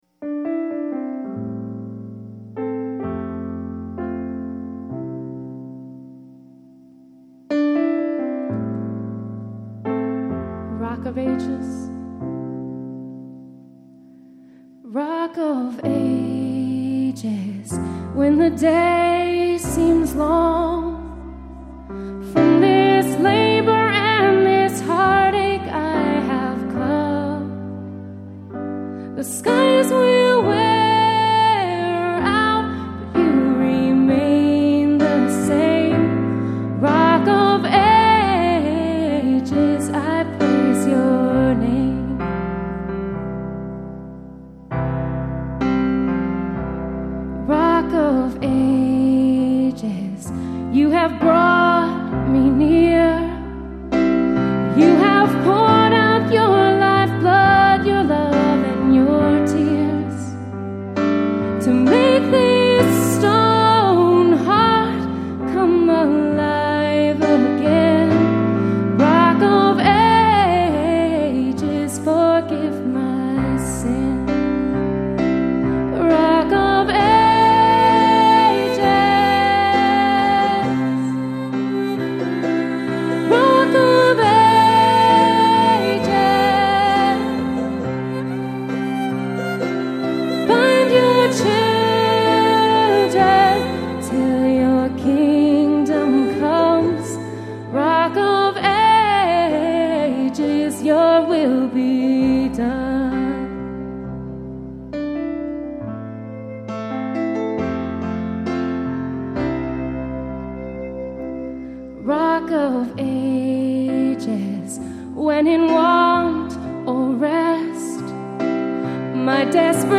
Wayfaring Stranger
Performed live at Terra Nova - Troy on 1/31/10.